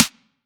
edm-snare-58.wav